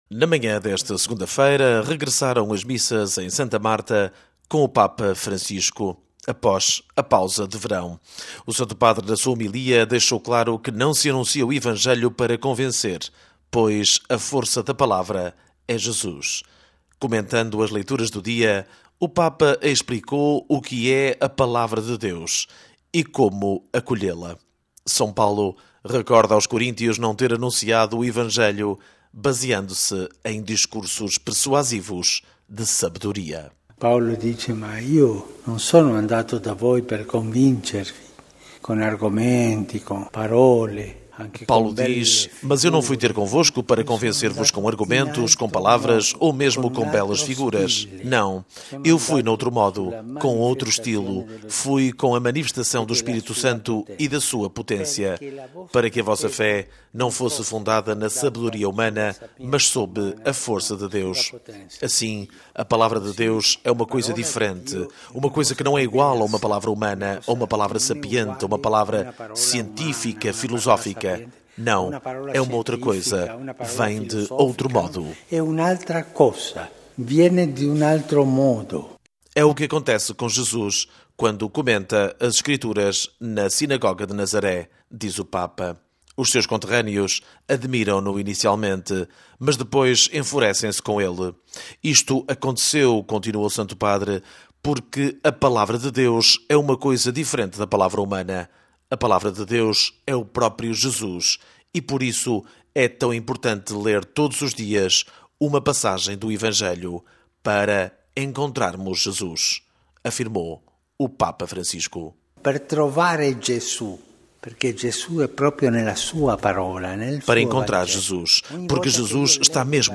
Não se anuncia o Evangelho para convencer. A força da Palavra é Jesus – o Papa Francisco na Missa em Santa Marta